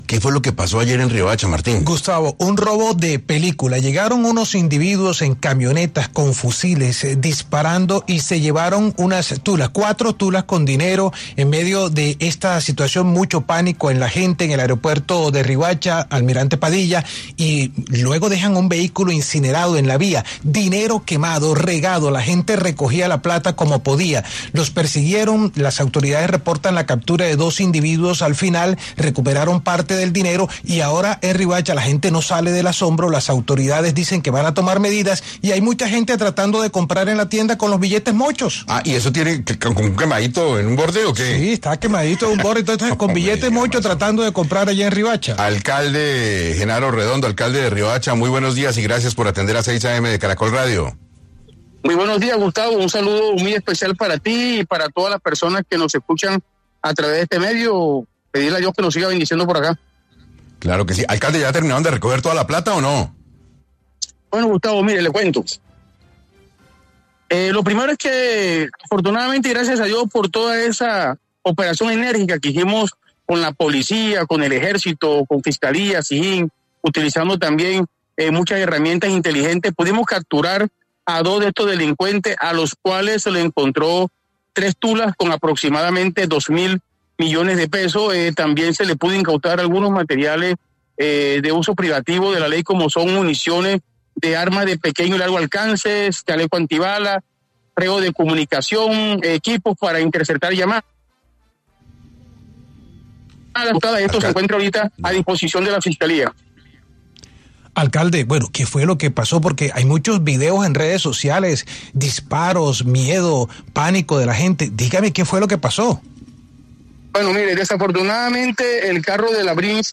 Genaro Redondo, alcalde de Riohacha, estuvo en 6AM para hablar del robo en el Aeropuerto de Riohacha.